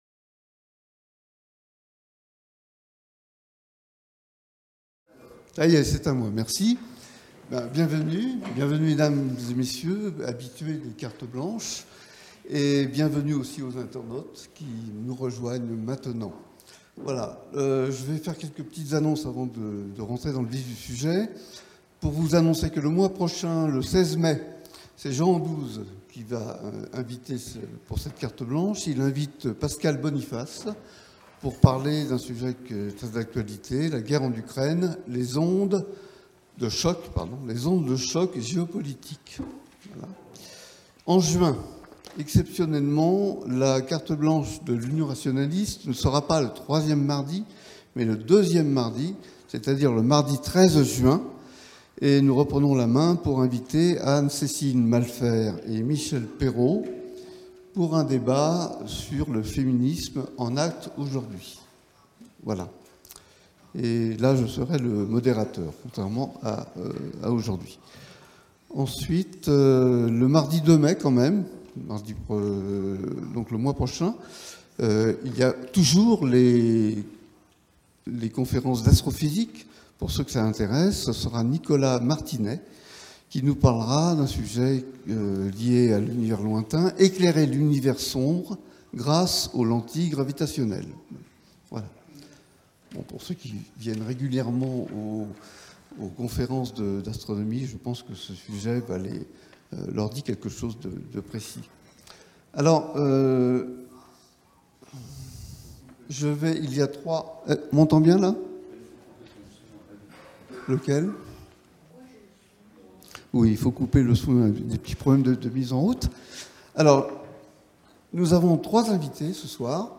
Jean-Louis Bianco (Secrétaire général de l’Élysée sous François Mitterrand et ex-président de l’Observatoire de la laïcité), débat avec Rachid Benzine (islamologue, politologue, enseignant et romancier, figure de l’islam libéral).